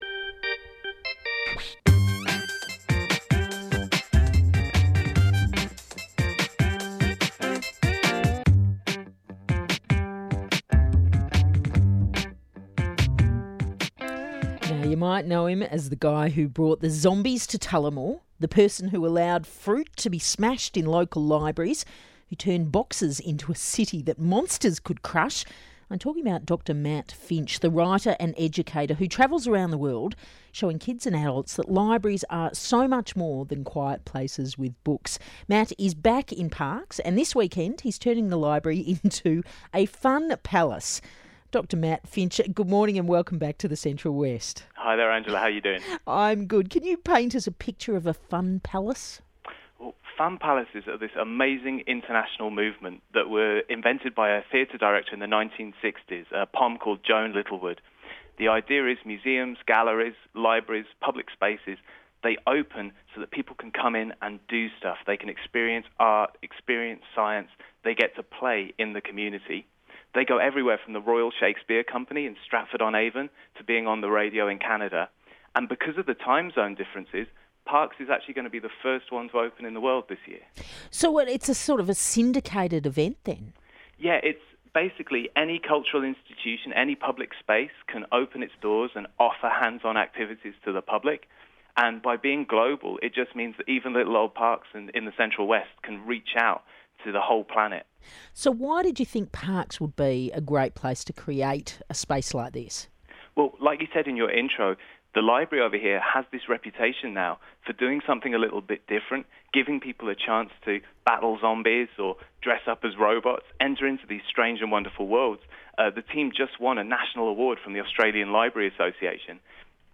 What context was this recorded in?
Photos and videos to follow – you can sneak a peek at the Parkes Library Facebook page if you like – but until then, here’s a radio interview I did with ABC Central West, speaking about Australia’s first Fun Palace and its basis in the work of British theatre director Joan Littlewood.